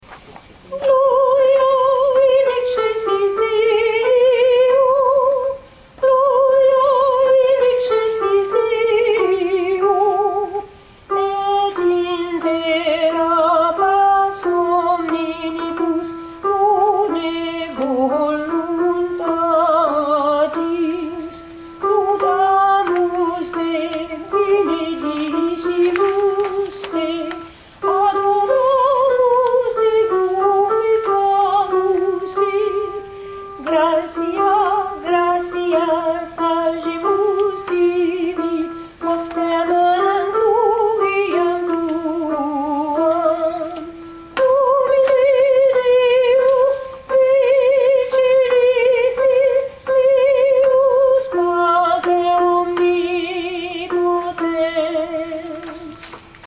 mp3 Alti gloria